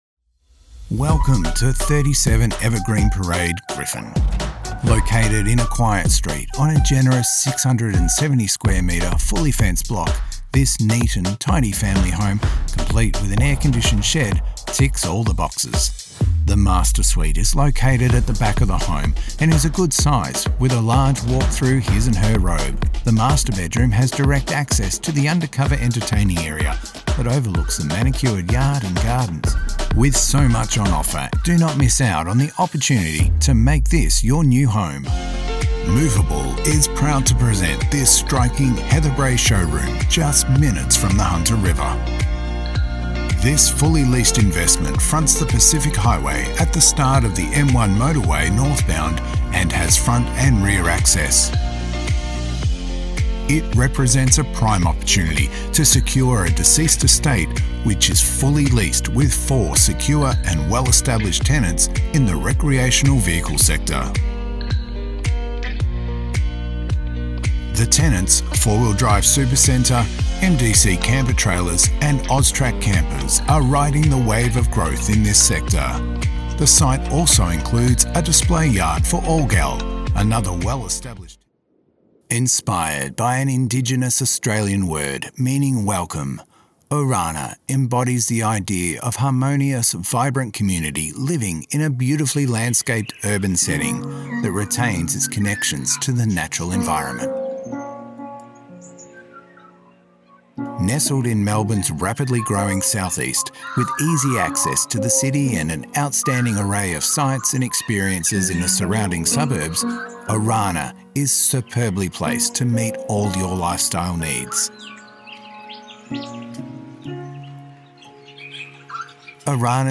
Male
English (Australian)
Casual, professional or announcer-ry, he brings every script to life.